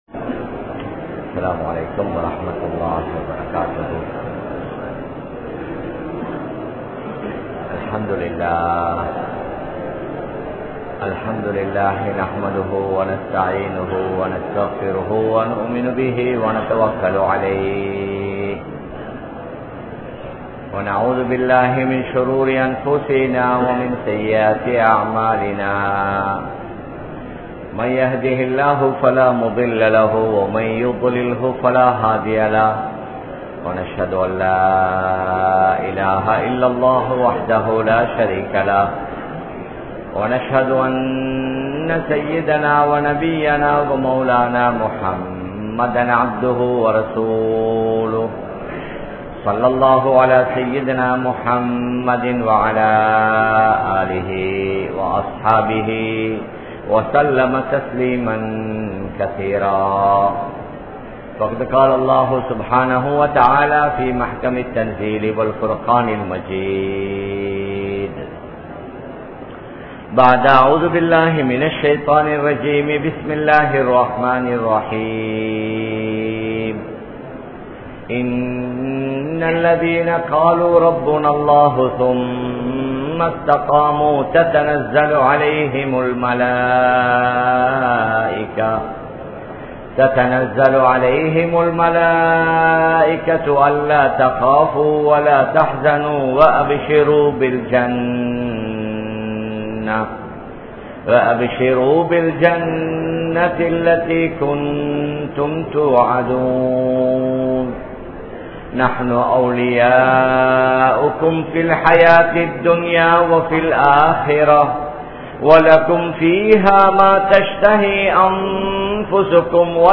Nabi(SAW)Avarhalin Dhauwath Murai (நபி(ஸல்)அவர்களின் தஃவத் முறை) | Audio Bayans | All Ceylon Muslim Youth Community | Addalaichenai